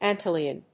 ánt lìon, ánt・lion